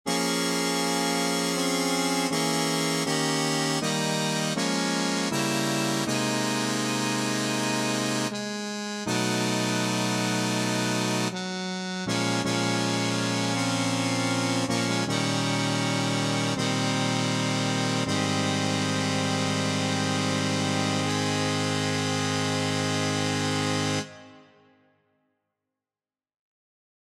Key written in: A♭ Major
How many parts: 4
Type: Barbershop
All Parts mix: